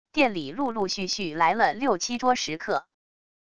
店里陆陆续续来了六七桌食客wav音频生成系统WAV Audio Player